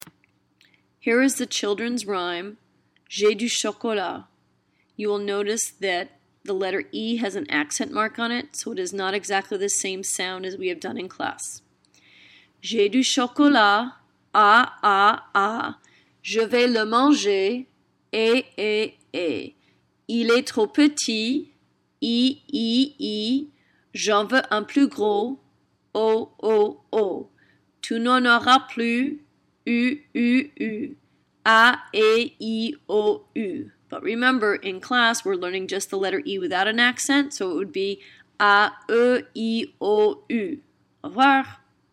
comptine/children's rhyme to learn sounds J'ai du chocolat A A A Je vais le manger é é é Il est trop petit i i i J'en veux un plus gros o o o Tu n'en auras plus u u u A é i o u !